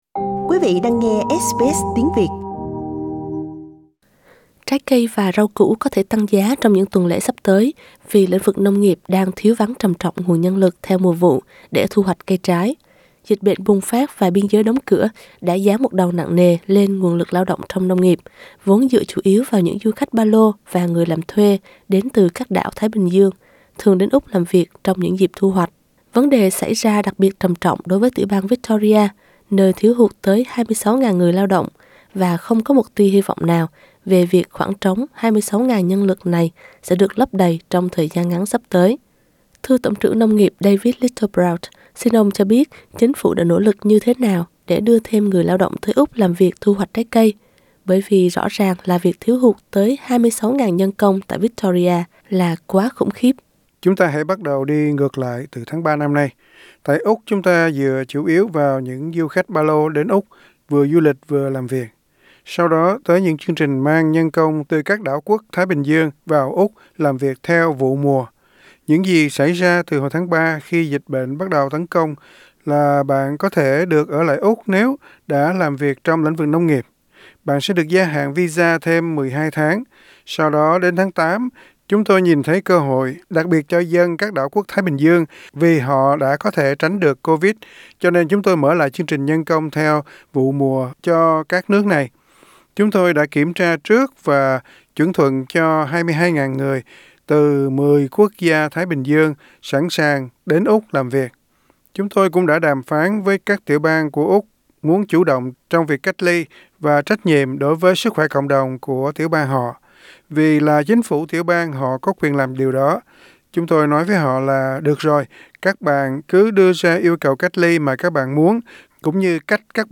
Trái cây và rau củ có thể tăng giá trong những tuần lễ sắp tới vì lĩnh vực nông nghiệp đang thiếu vắng trầm trọng nguồn nhân lực theo mùa vụ để thu hoạch cây trái. Ali Moore của đài ABC đã phỏng vấn Tổng trưởng Nông nghiệp David Littleproud, tìm hiểu tại sao không có đủ nhân lực trong ngành để làm việc này.